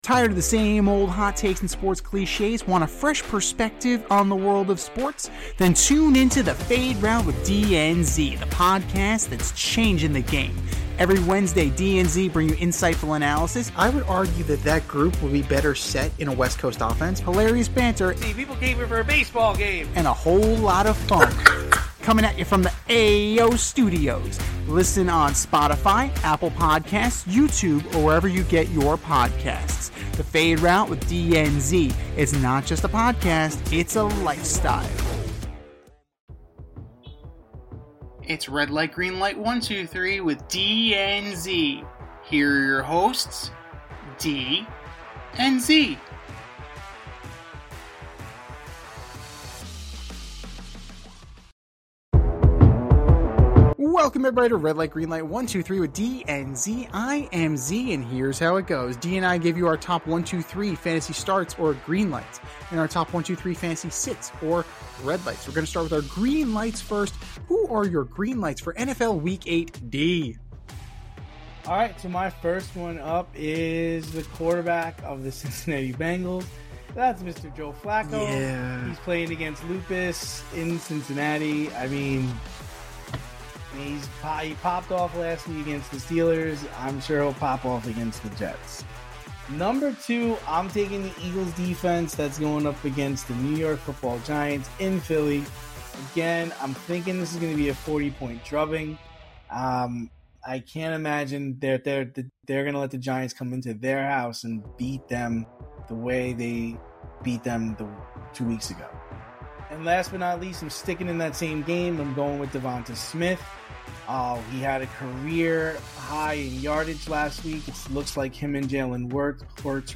two veteran sports aficionados and lifelong friends
with wit and a touch of New York flair